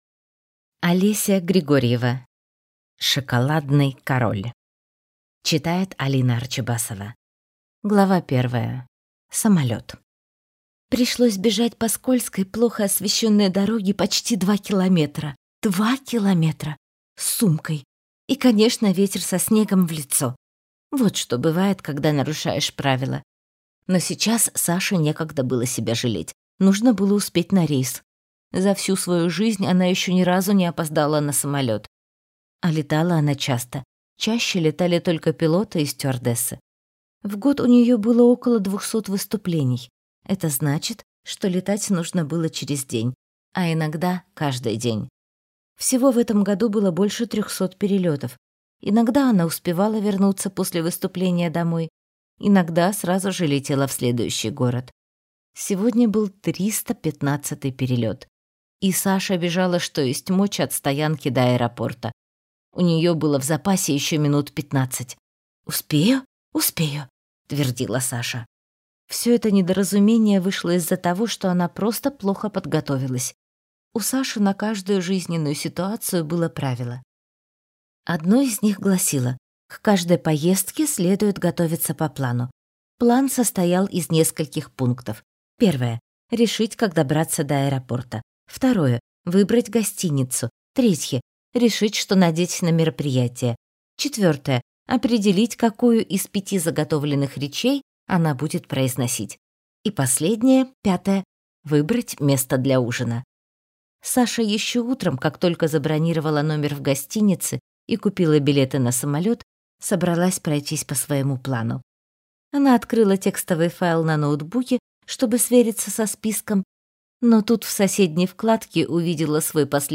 Аудиокнига Шоколадный король | Библиотека аудиокниг